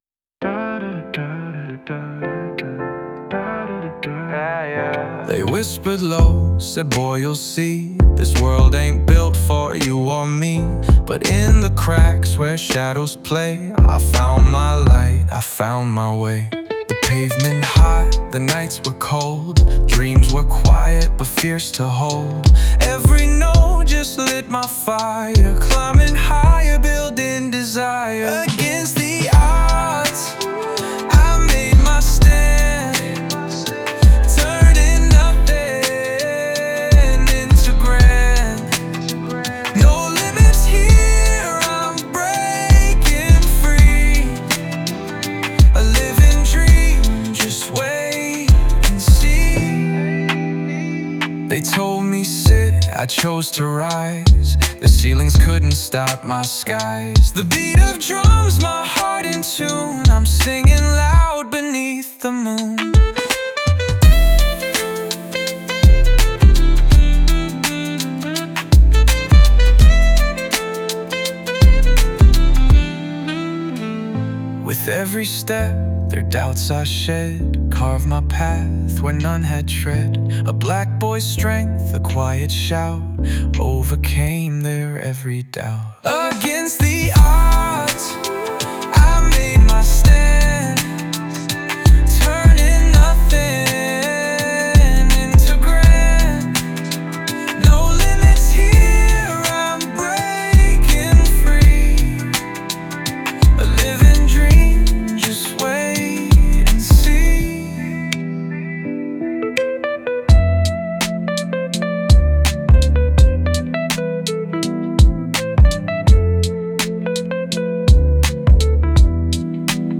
Jazz, R&B